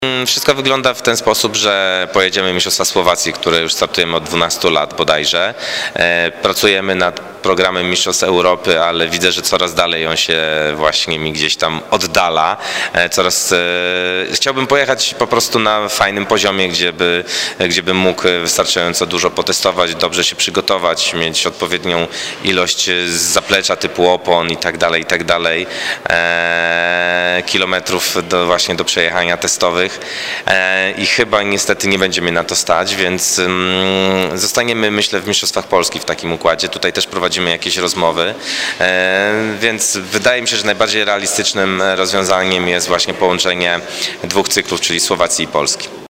Obszerny wywiad poniżej.